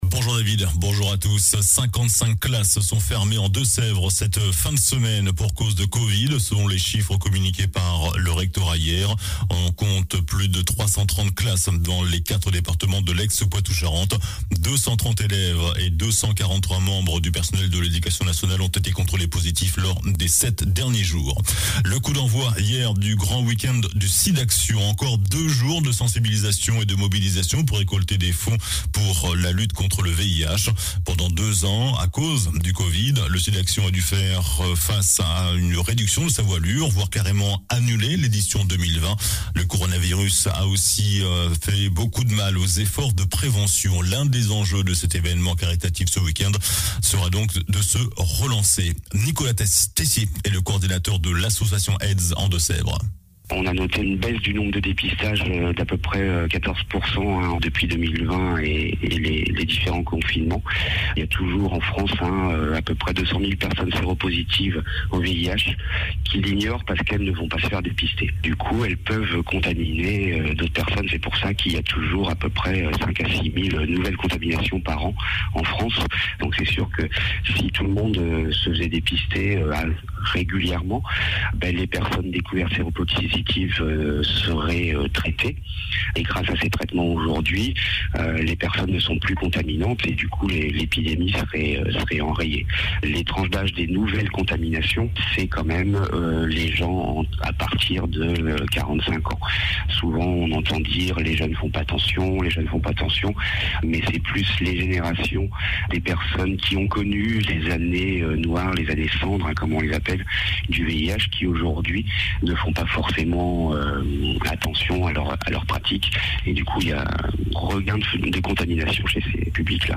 JOURNAL DU SAMEDI 26 MARS